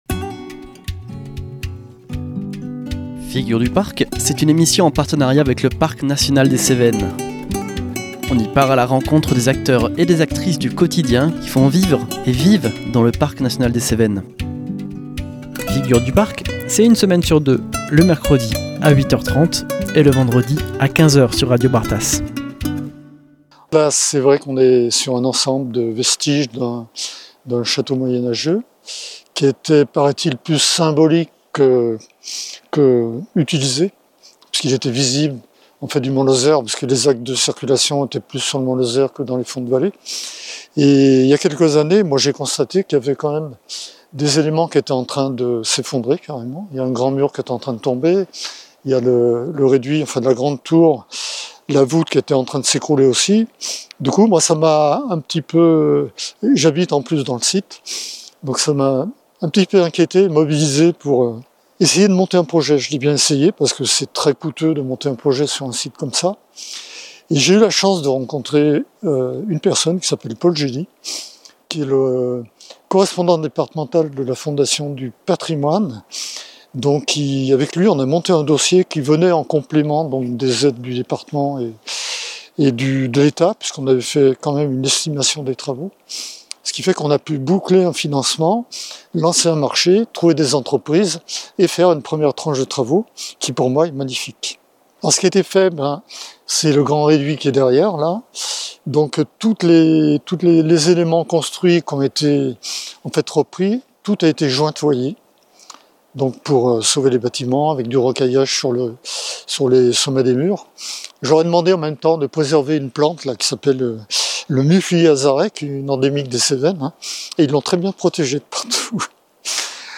Nous sommes allés à la rencontre de Pascal Beaury, maire de Mont Lozère et Goulet, sur le site du château du Tournel. Les ruines de cette imposante forteresse, posée sur un piton rocheux enserré par le Lot, font l’objet de travaux de restauration depuis plusieurs années, grâce notamment à la Fondation du patrimoine.